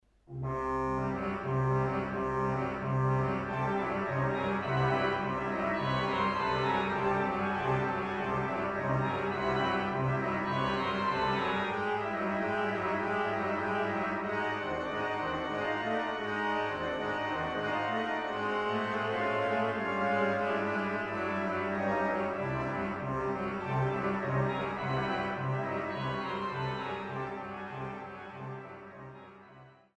Oberlinger Orgel der Stadtkirche Dillenburg
Zwölf Orgelstücke